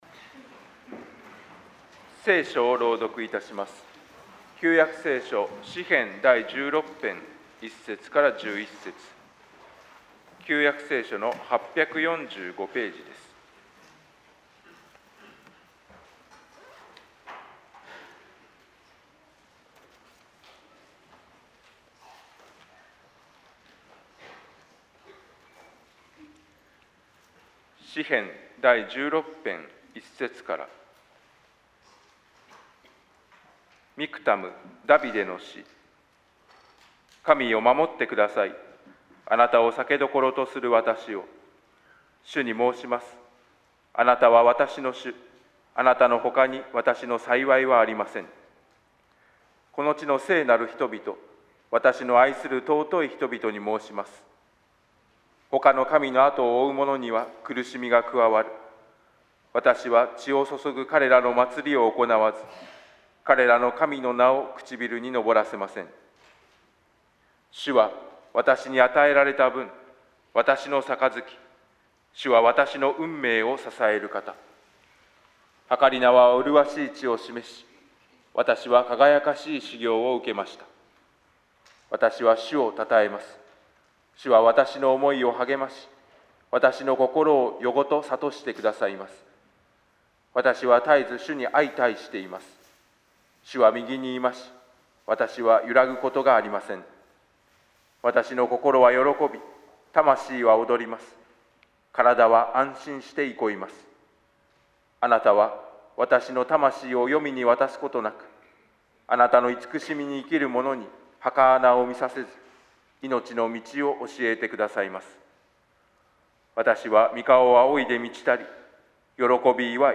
説 教 「初穂となられたキリスト」